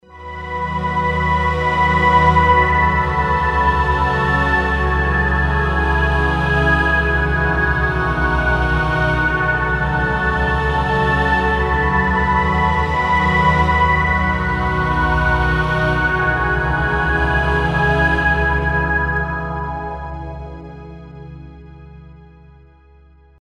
pad 2
demo-pad2.mp3